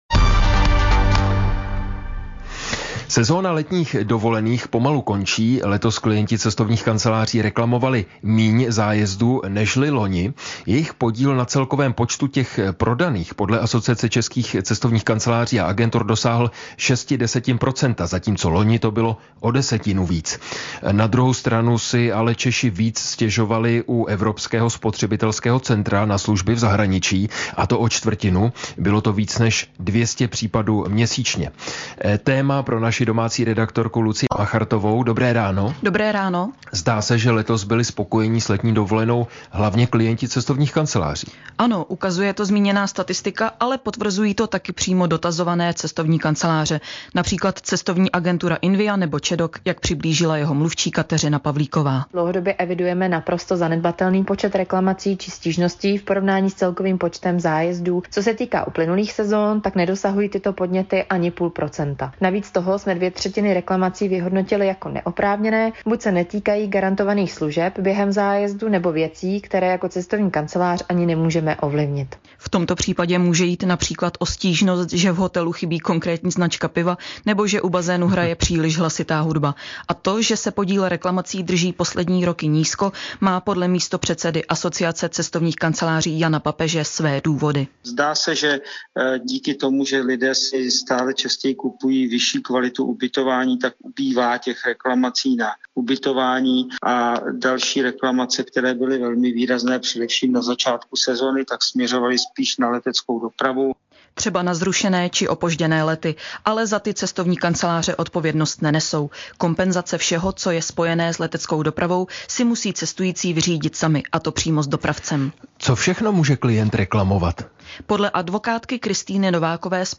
Ranní Plus: rozhovor,